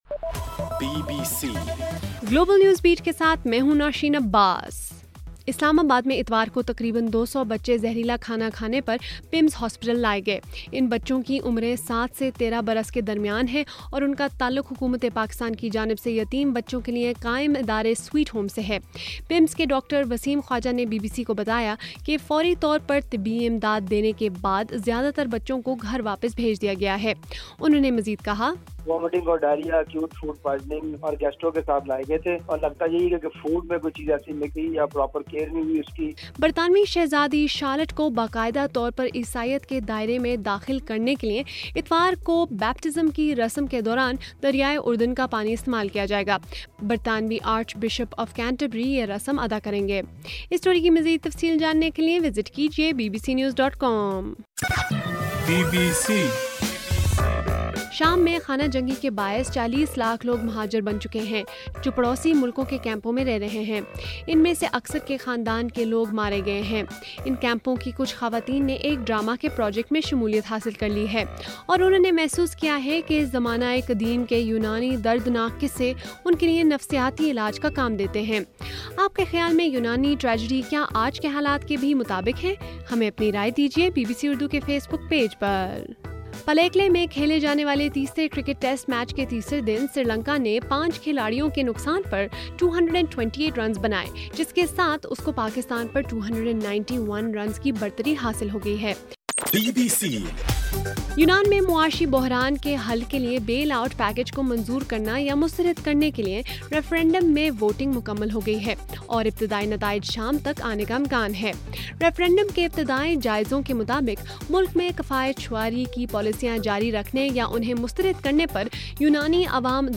جولائی 6: صبح1 بجے کا گلوبل نیوز بیٹ بُلیٹن